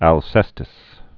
(ăl-sĕstĭs)